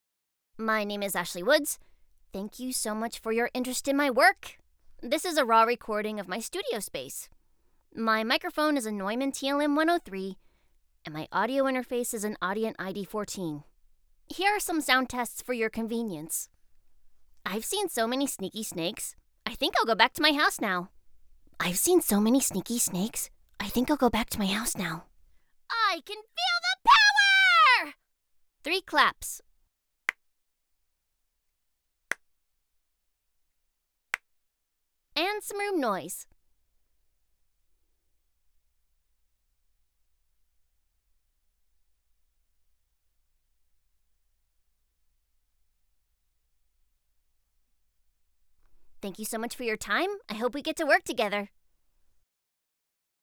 Professional Voice Actress